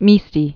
(mēstē), El